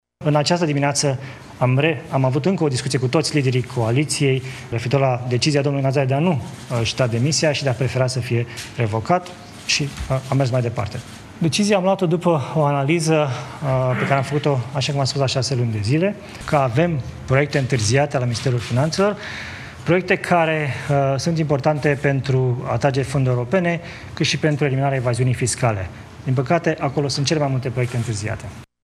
Florin Cîțu a vorbit despre remaniera Guvernului și s-a declarat nemulțumit de întârzierile de la Ministerul Finanțelor: